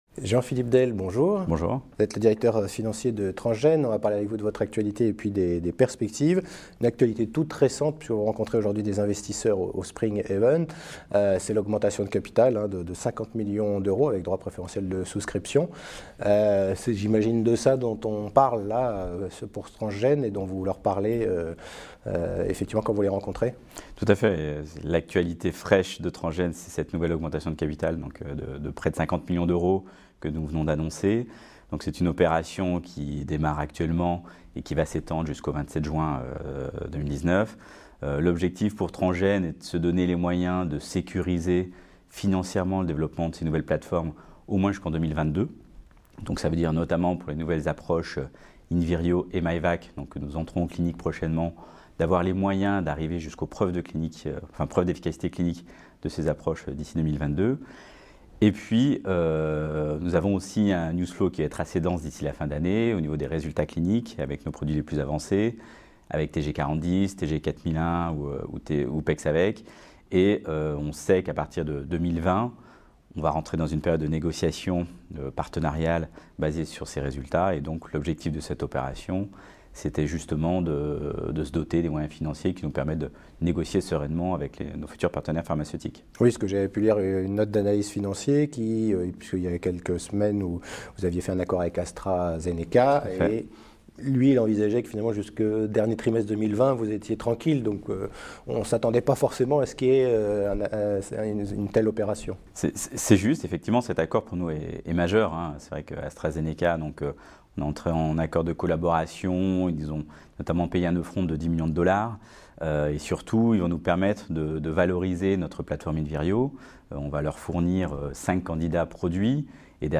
Vous vous intéressez à la société Transgene, retrouvez toutes les interview des dirigeants déjà diffusée sur laWeb Tv via ce lien : Vidéos Transgene